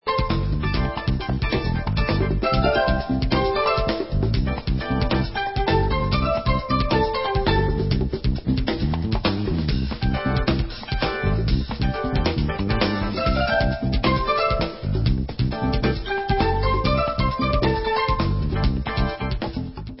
FUSION